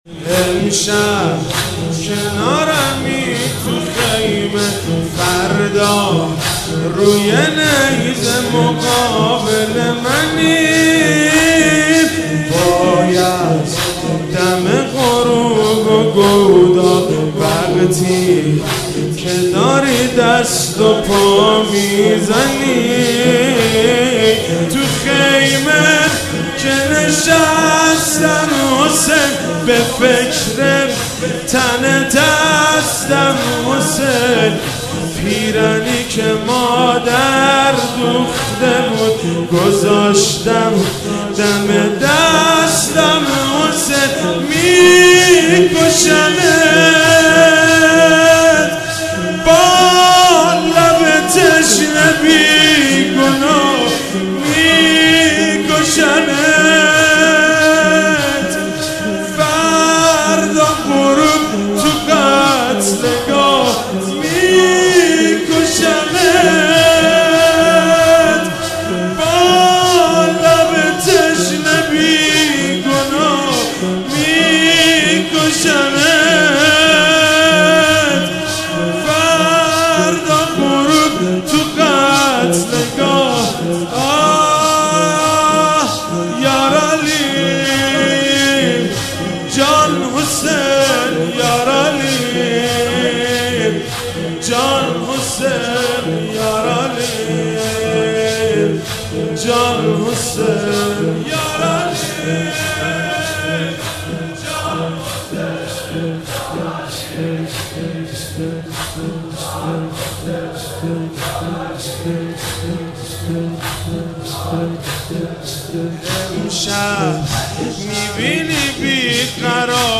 هیئت انصار الحجه مشهد مقدس ------------------------------------------------------ شام عاشورا محرم 94
زمینه مداحی